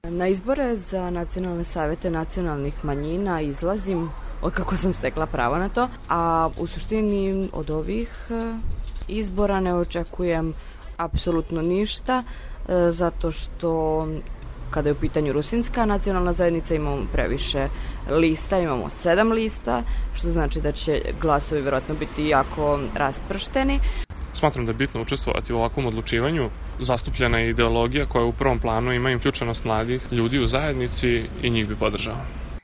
Građani na glasačkom mestu u Novom Sadu